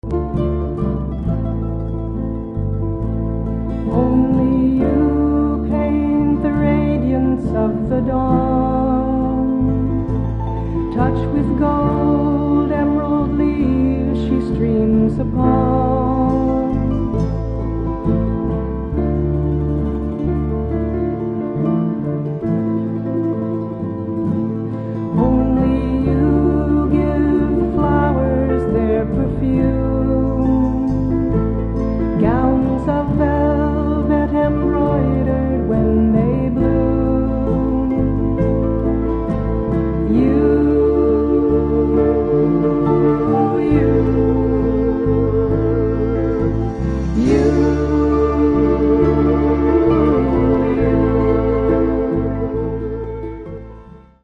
Songs of Devotion and Meditation in English